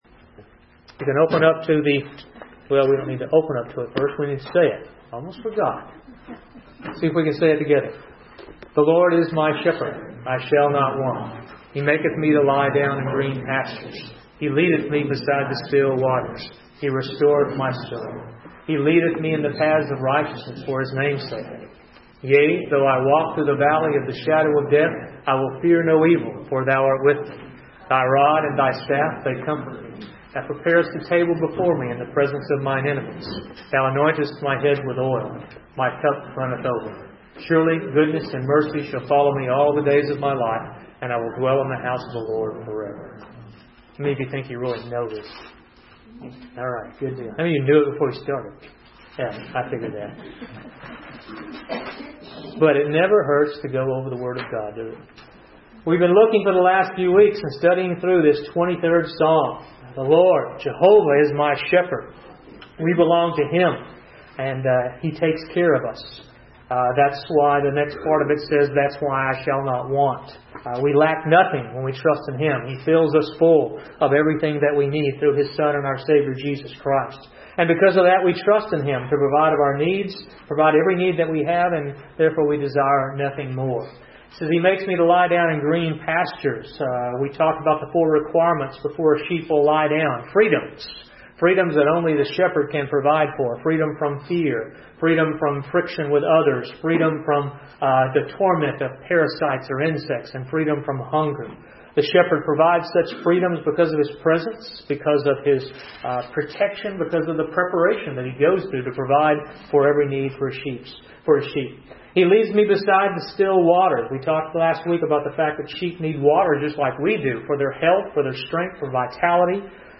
Listen to Bible messages from our Wednesday Evening Bible Studies (all Bible Study Sermons are in MP3 format). Wednesday evening sessions are more informal than weekend services.